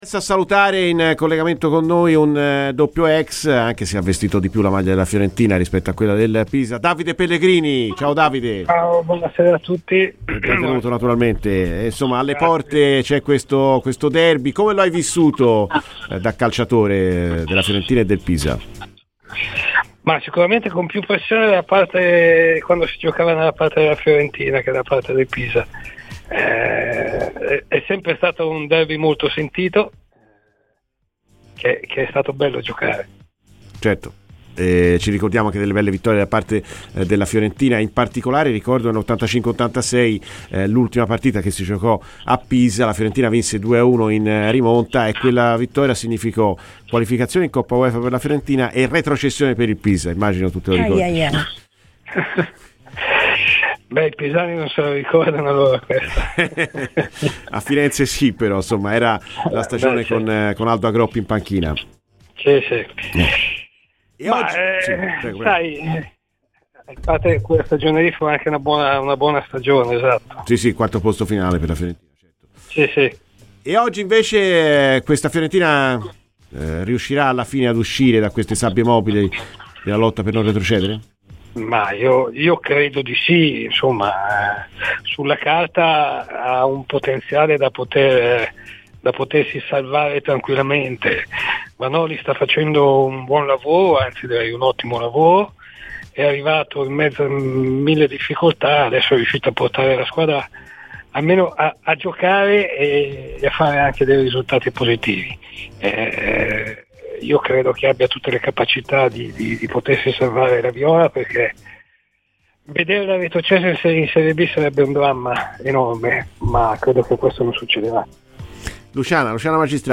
Vanoli gli ha fatto fare un lavoro importante per recuperarlo dall'infortunio": Ascolta il podcast per l'intervista completa.